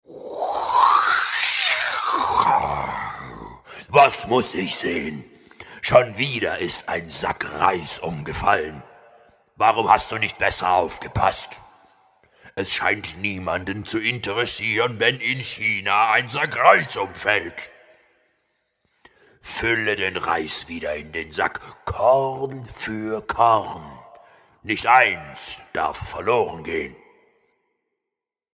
tiefe, markante, wandlungsfähige, kernig, warme, Bassstimme, Charakter
Sprechprobe: eLearning (Muttersprache):